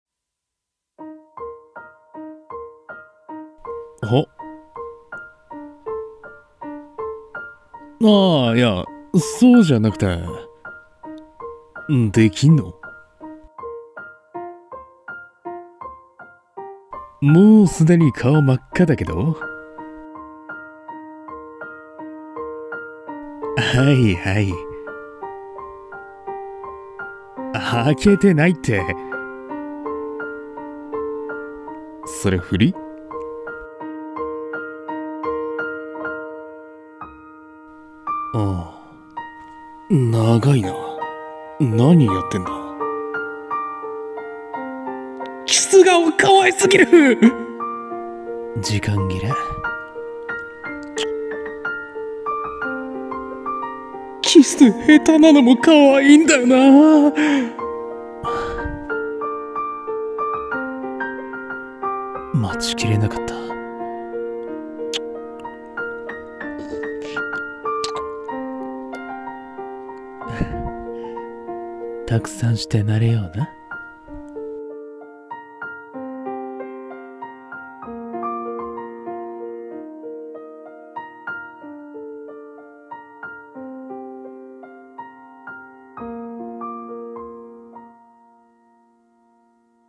【二人声劇】ツンデレ彼女からのキス